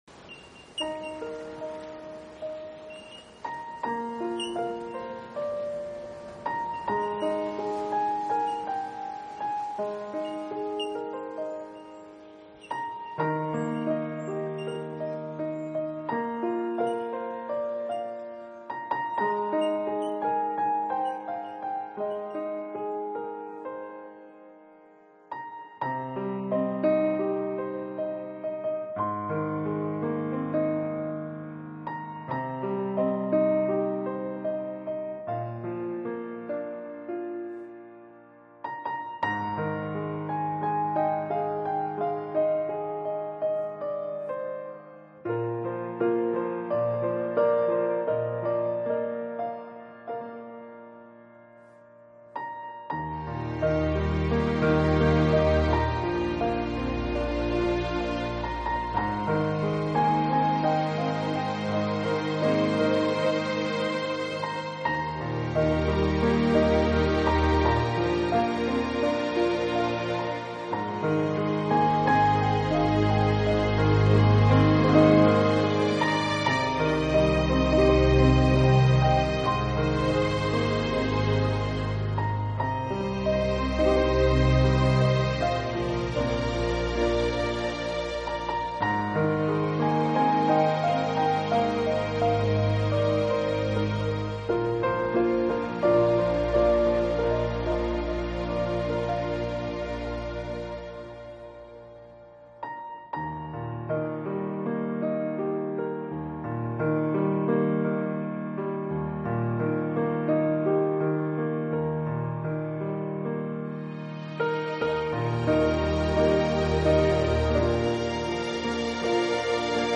音乐风格：New Age
乐方面的纯美境界，华美的钢琴旋律配以浪漫动人的竖琴和古典吉他，还有特别
柔情的管弦乐，每一曲都是一幅独特唯美的音乐诗画。